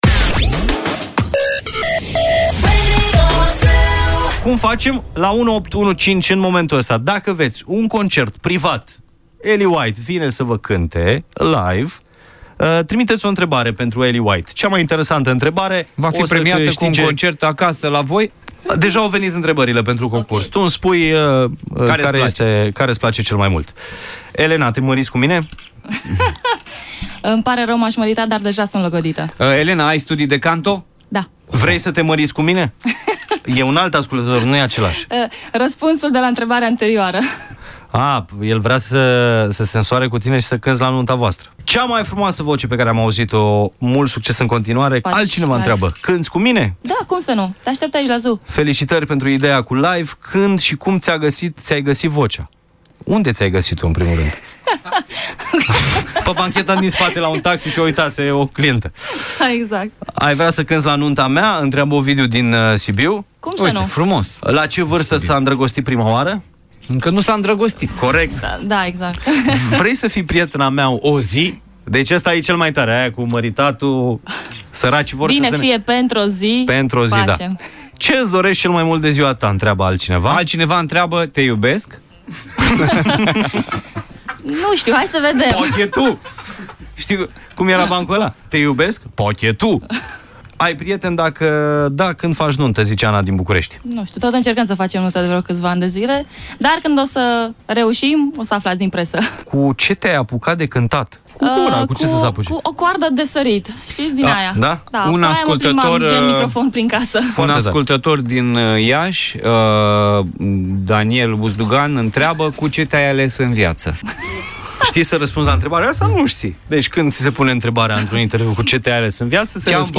De ziua ei, Ellie White ne-a facut o vizita in studio.